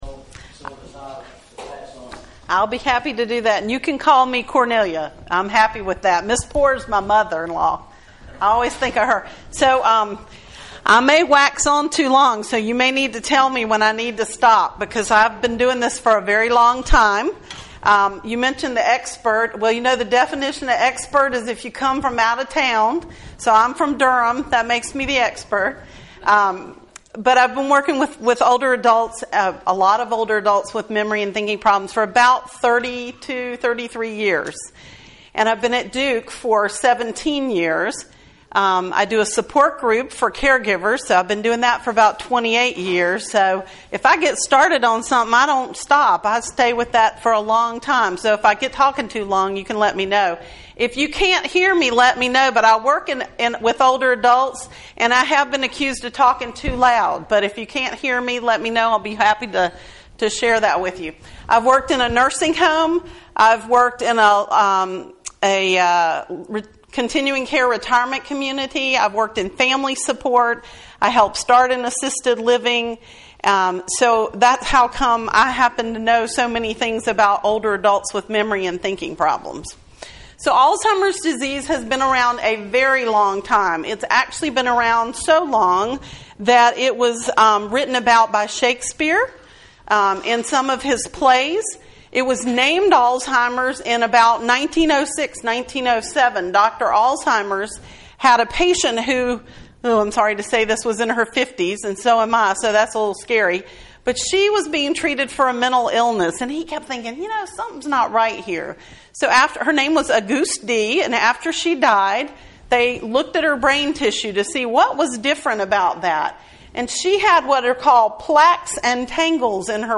Community Watch Meetings